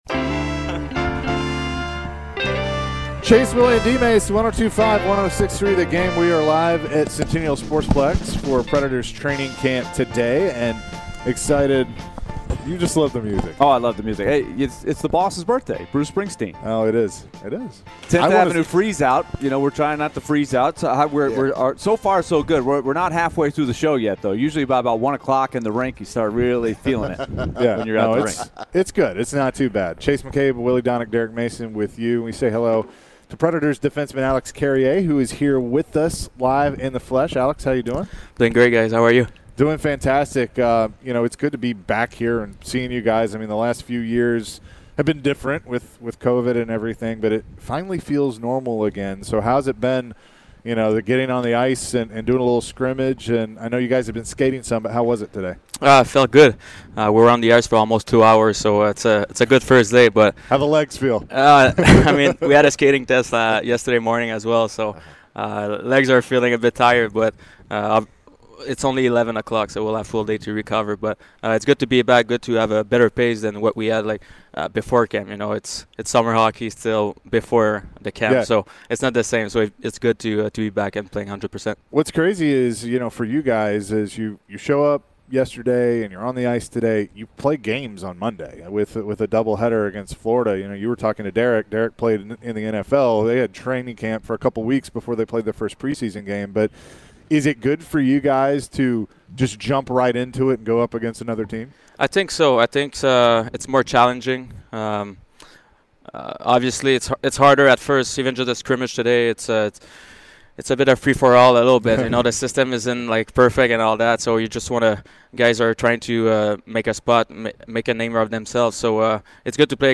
Alexandre Carrier interview (9-23-22)